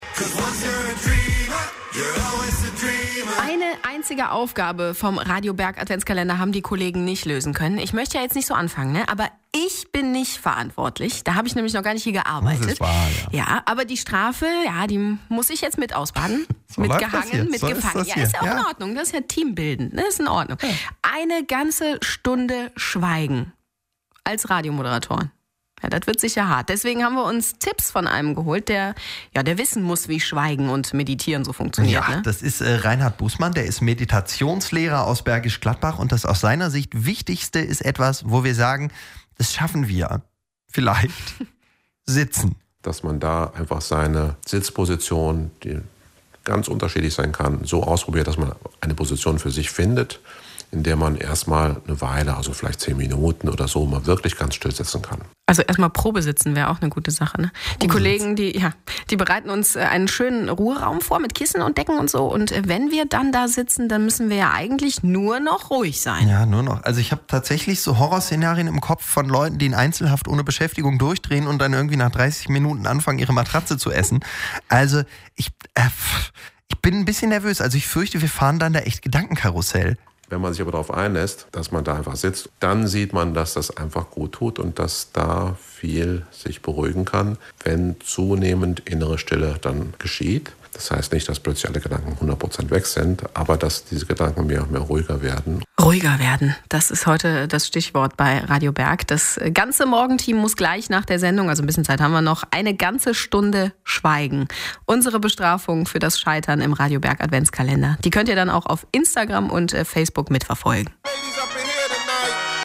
aircheck1_meditation.mp3